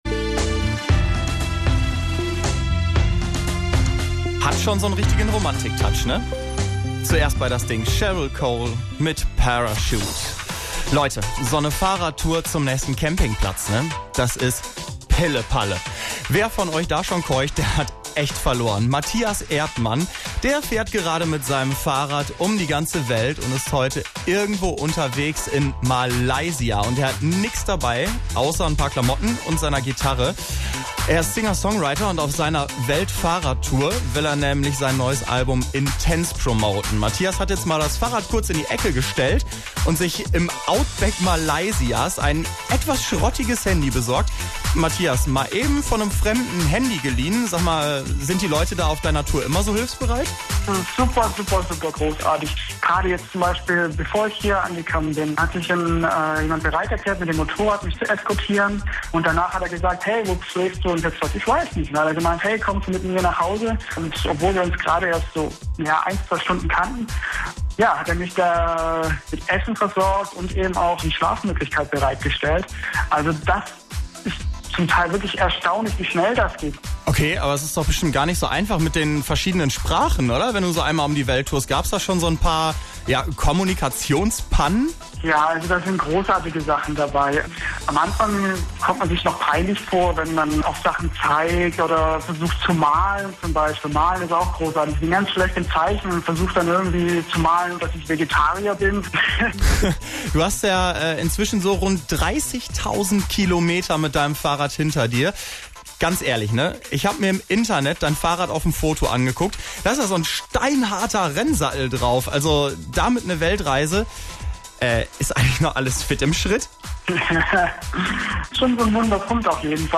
radio interview
the interview was broadcasted on september the 12th.
the song ‘mystique’ was played but is not included in this file. you can listen to it in full length here.